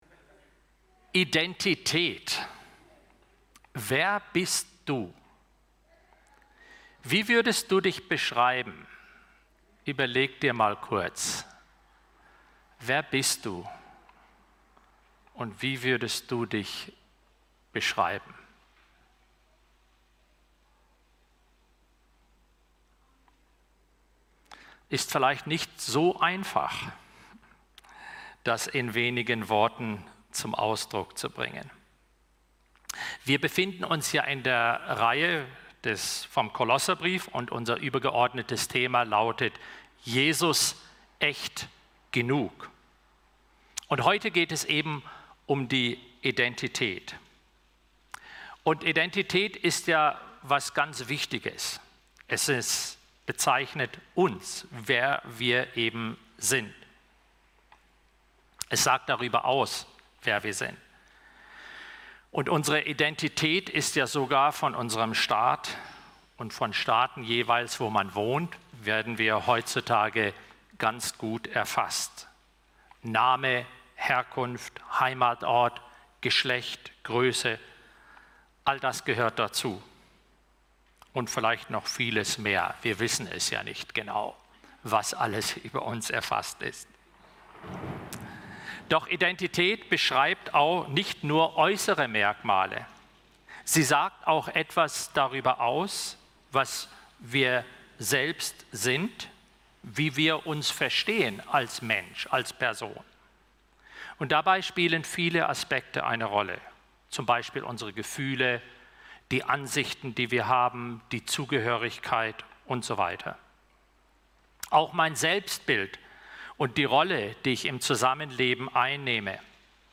Gottesdienst-Jesus.-Echt.-Genug.-Identitaet-Du-bist-mehr-als-Du-denkst-Kol-3-1-11-Viva-Kirche-Grueningen.mp3